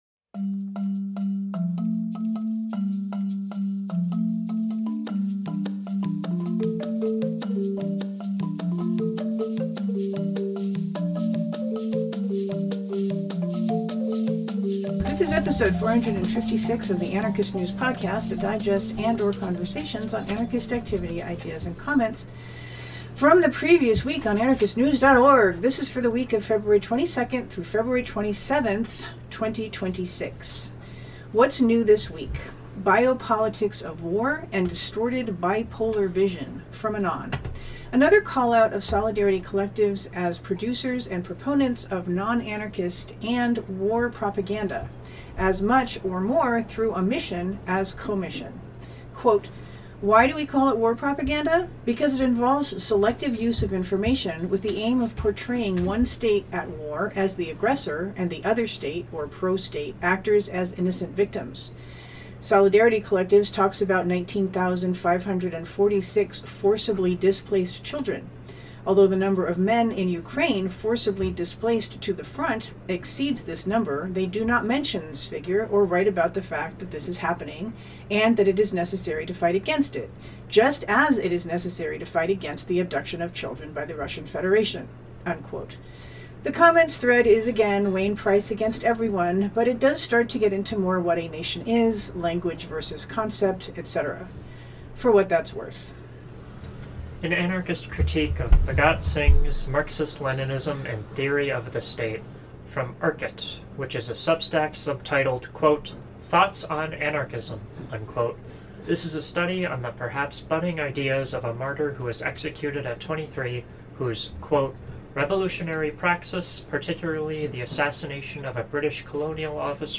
music samples